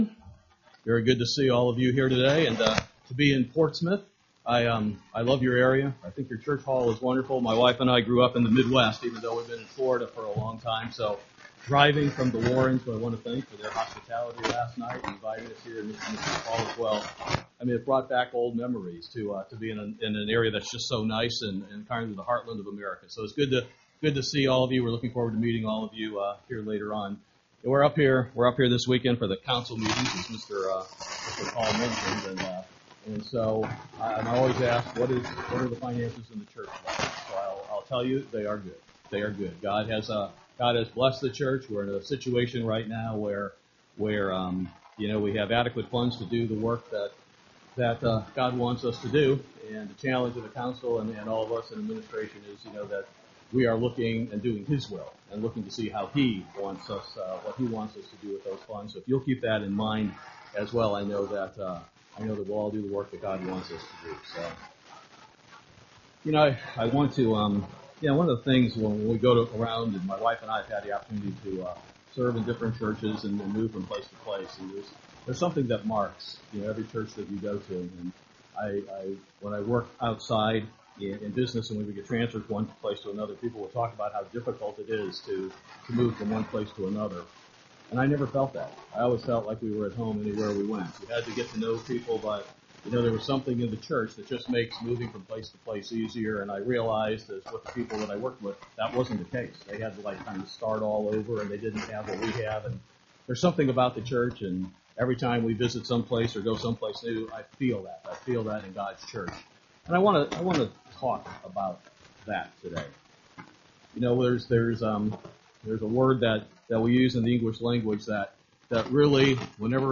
Agape Love | United Church of God